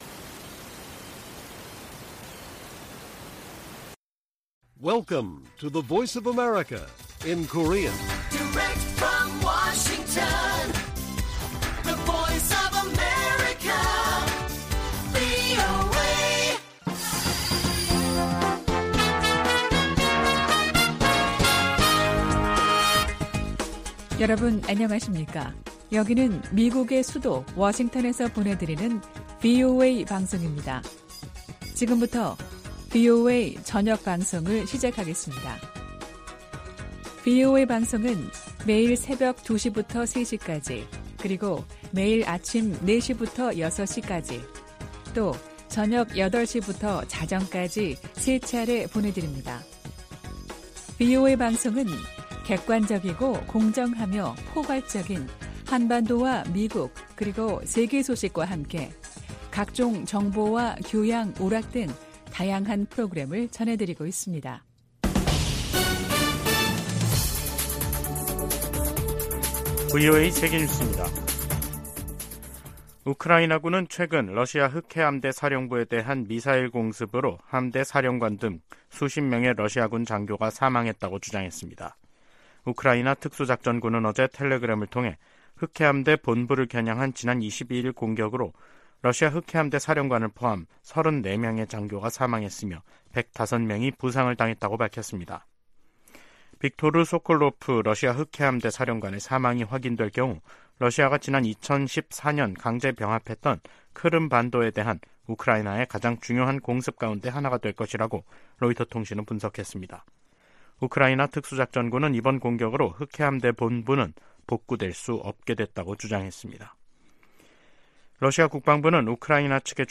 VOA 한국어 간판 뉴스 프로그램 '뉴스 투데이', 2023년 9월 26일 1부 방송입니다. 토니 블링컨 미 국무장관은 미한 동맹이 안보 동맹에서 필수 글로벌 파트너십으로 성장했다고 평가했습니다. 한국과 중국, 일본의 외교당국은 3국 정상회의를 빠른 시기에 개최하기로 의견을 모았습니다.